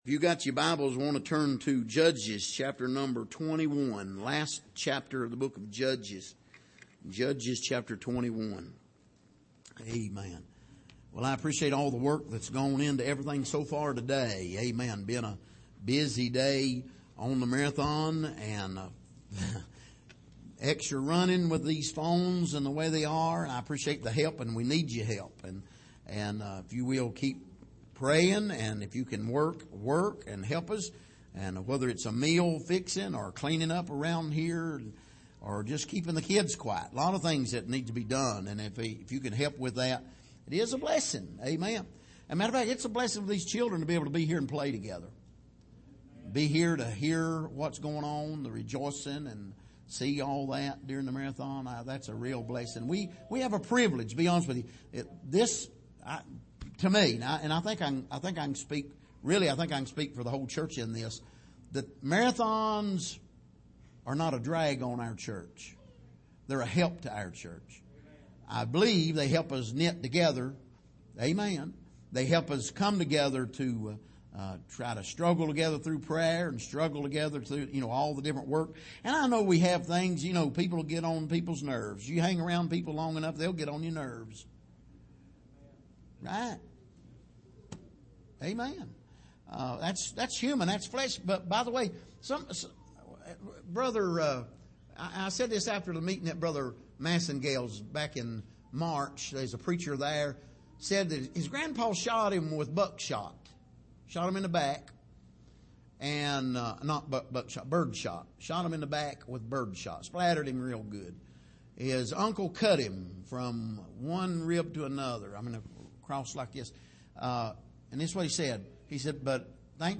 An Overview of Judges Passage: Judges 21:25 Service: Midweek